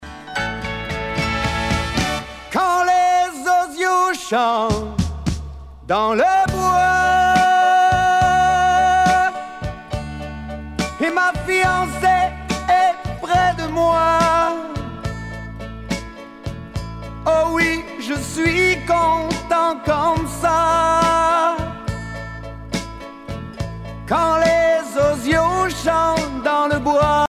danse : slow